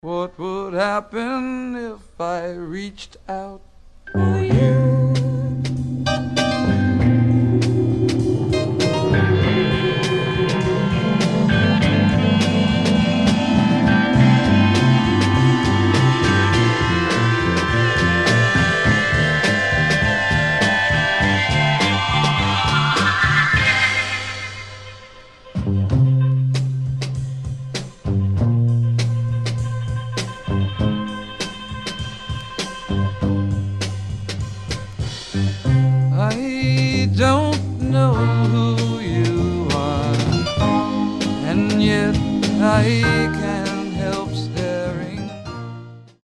60’s rock group